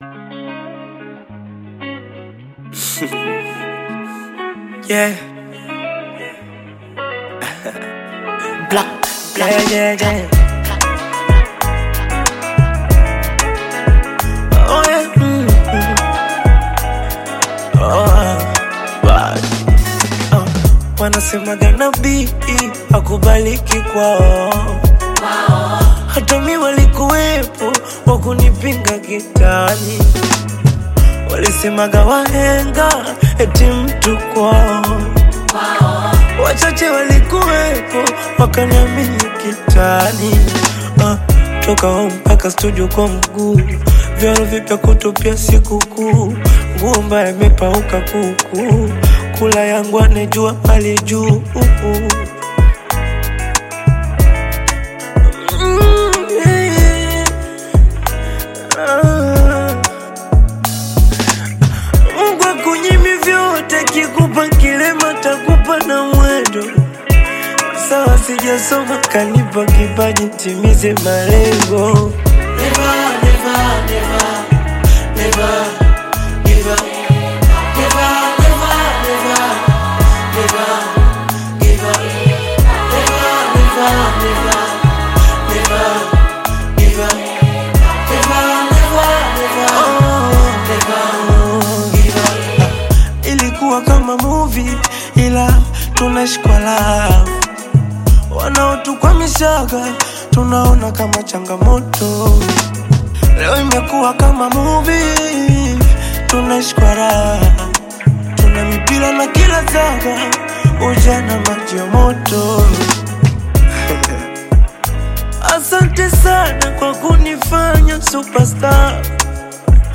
Tanzanian bongo flava recording artist